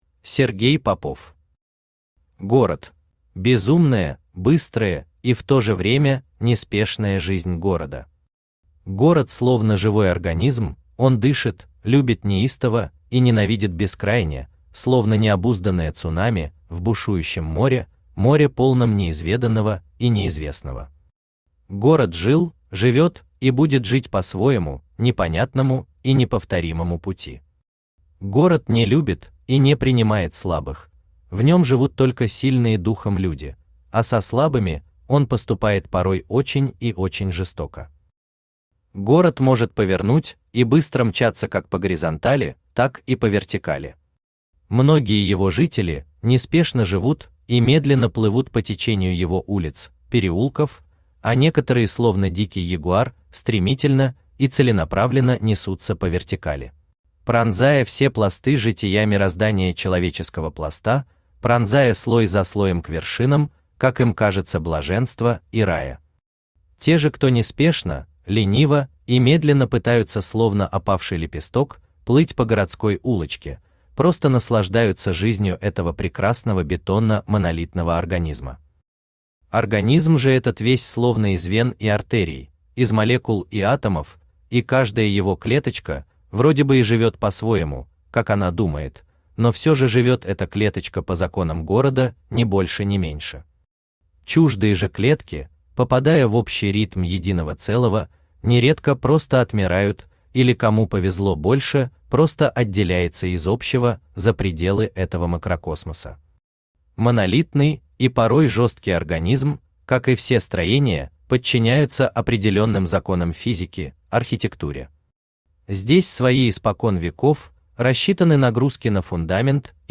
Aудиокнига Город Автор Сергей Андреевич Попов Читает аудиокнигу Авточтец ЛитРес.